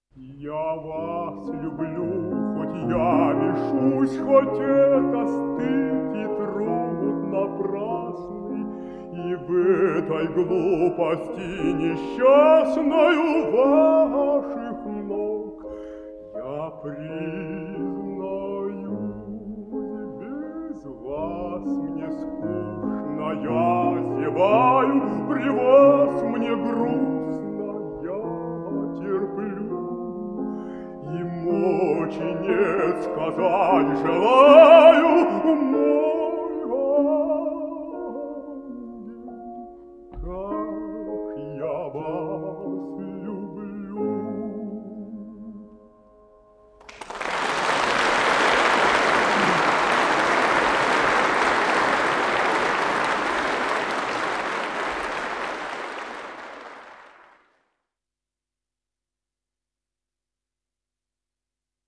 Жанр: Вокал
ф-но
Большой зал Консерватории
баритон